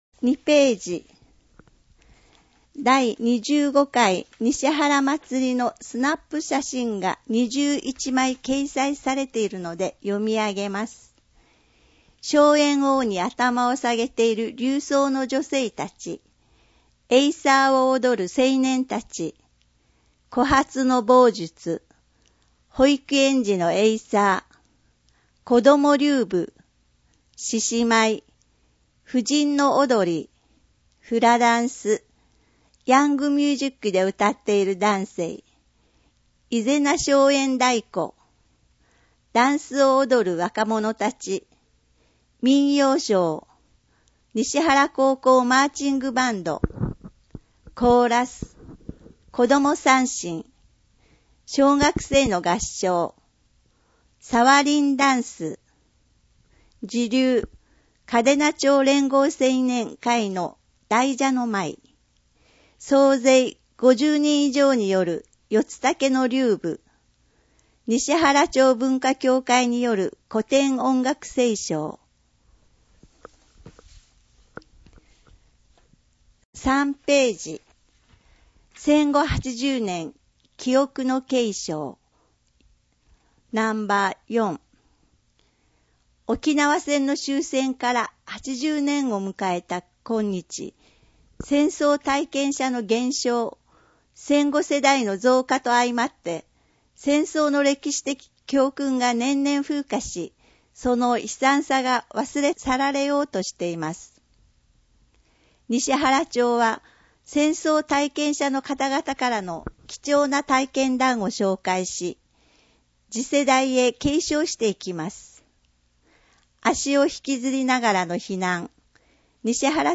声の広報にしはらは、広報にしはらの情報を音声でお届けしています。
音訳ボランティアサークル「声の広報かけはし」が録音しています。